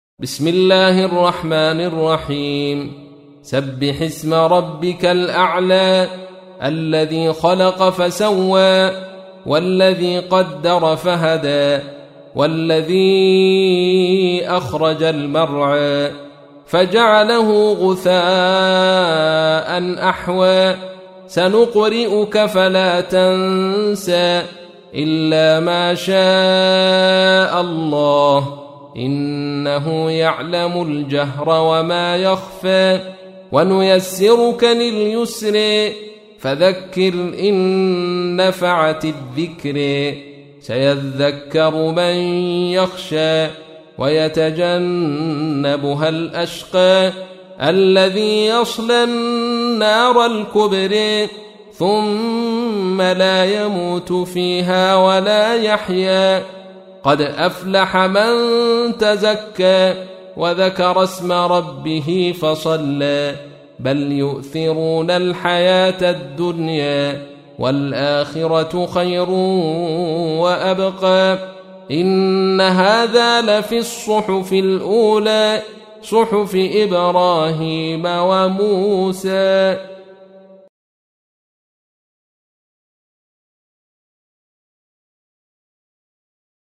تحميل : 87. سورة الأعلى / القارئ عبد الرشيد صوفي / القرآن الكريم / موقع يا حسين